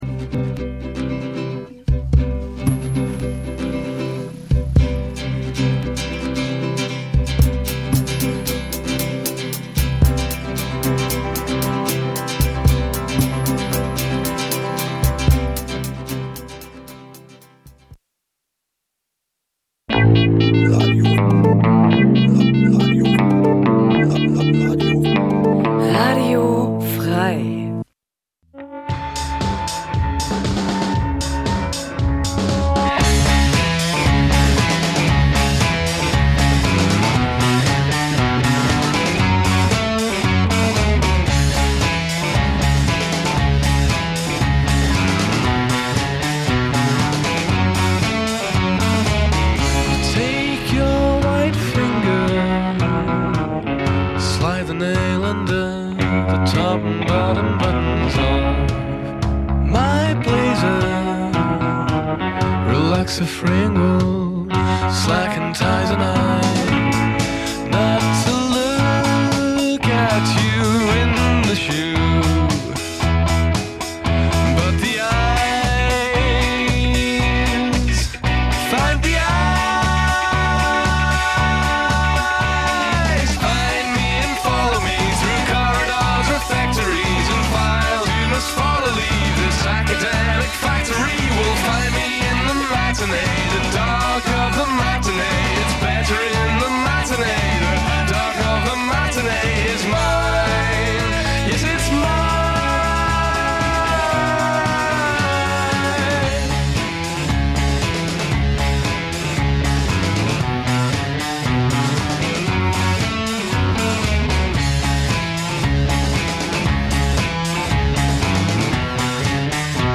Im Vorfeld der regul�ren Partyzone h�ren wir alle 4 Wochen Interviews DJs, Veranstaltungen des Abends und �hnliches